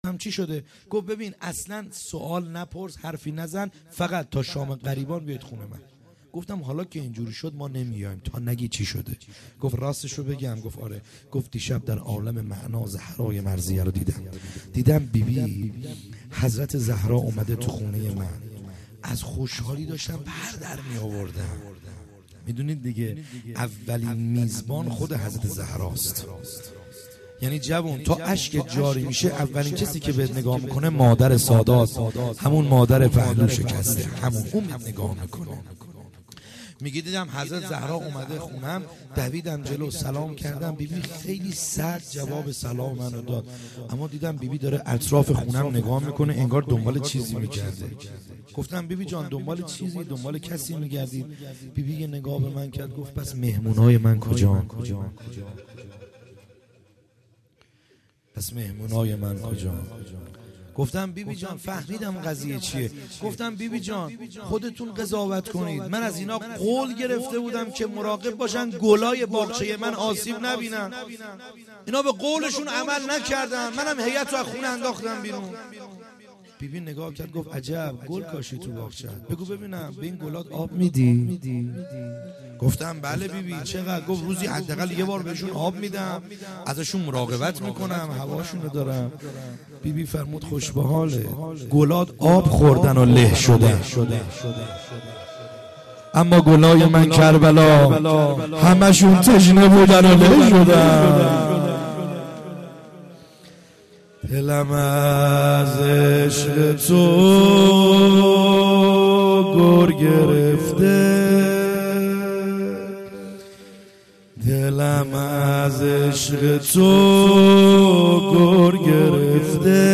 خیمه گاه - بیرق معظم محبین حضرت صاحب الزمان(عج) - روضه